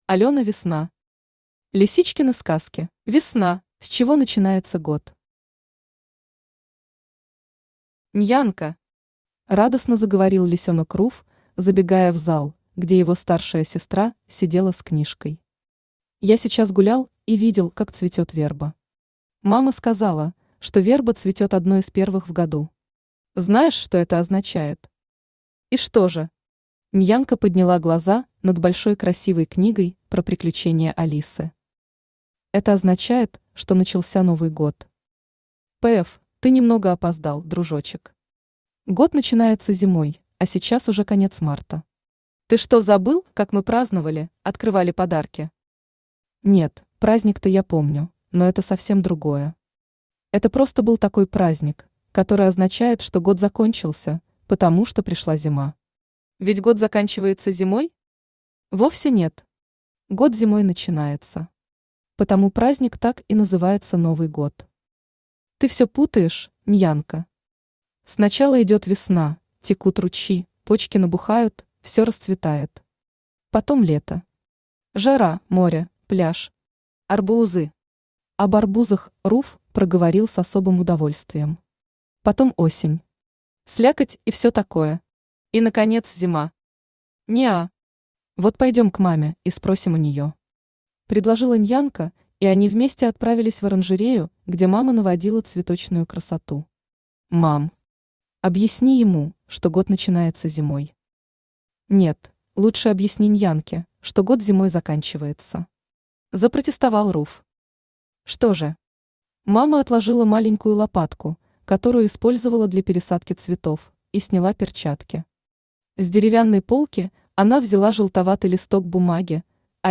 Аудиокнига Лисичкины сказки | Библиотека аудиокниг
Aудиокнига Лисичкины сказки Автор Алёна Весна Читает аудиокнигу Авточтец ЛитРес.